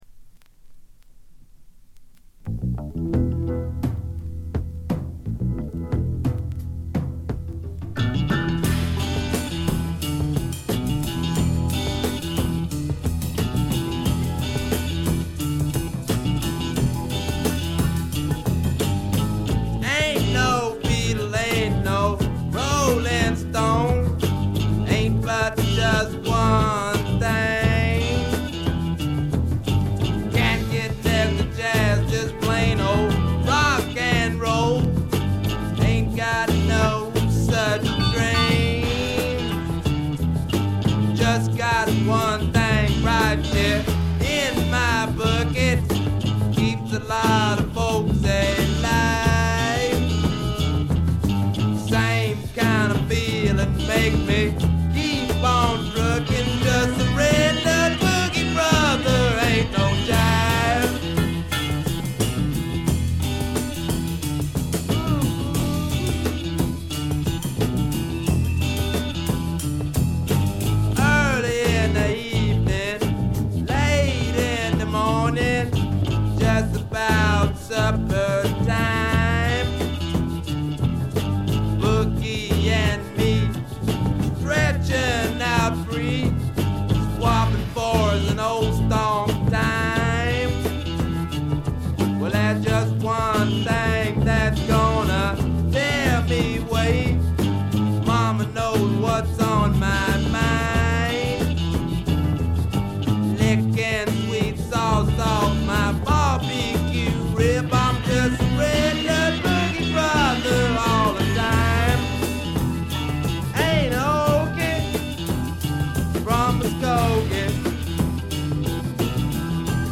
ほとんどノイズ感無し。
いうまでもなく米国スワンプ基本中の基本。
試聴曲は現品からの取り込み音源です。